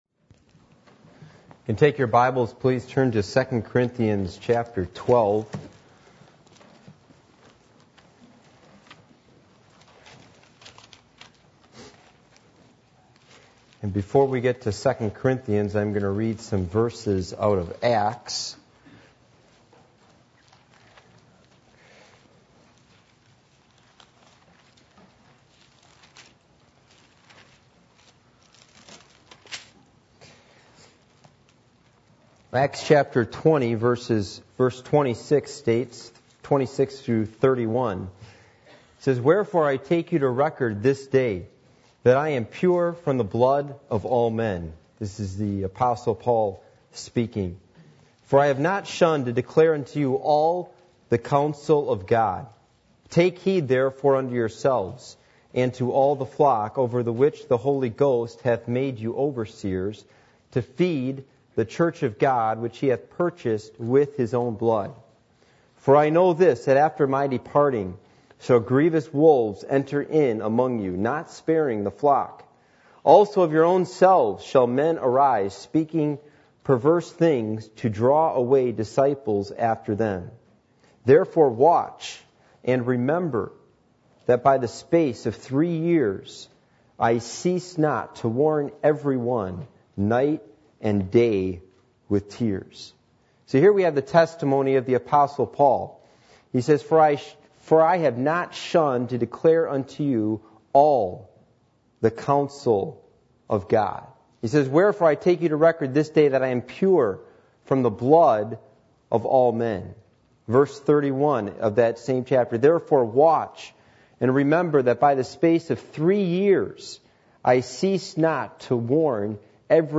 Passage: 2 Corinthians 12:11-21 Service Type: Sunday Evening %todo_render% « Judgement Is Coming!